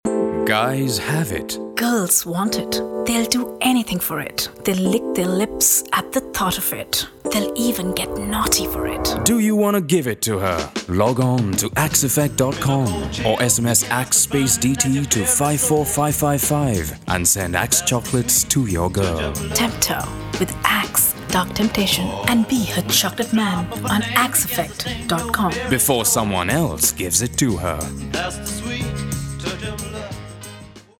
Indian, Hindi, Punjabi, Neutal Accent, Friendly, Sexy, Conversational
Sprechprobe: Werbung (Muttersprache):